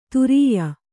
♪ turīya